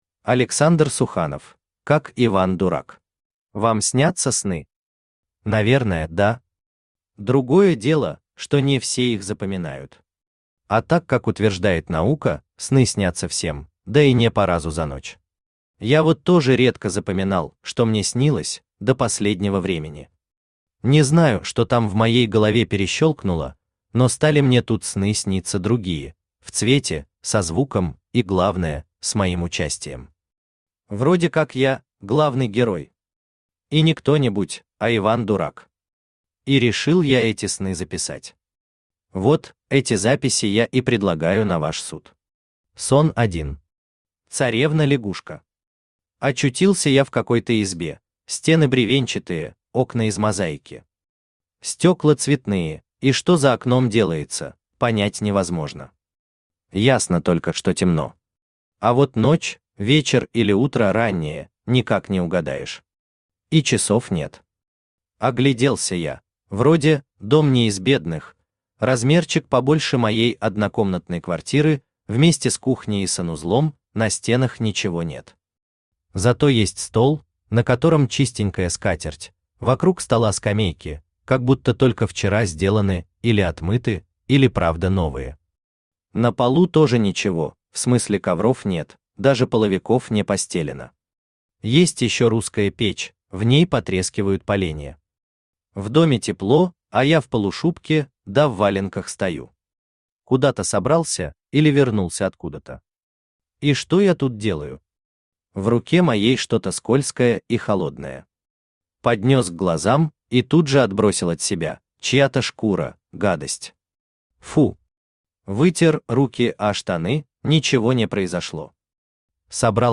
Аудиокнига Как Иван-Дурак | Библиотека аудиокниг
Aудиокнига Как Иван-Дурак Автор Александр Суханов Читает аудиокнигу Авточтец ЛитРес.